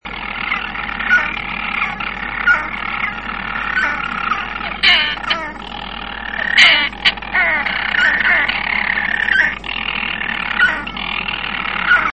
głosy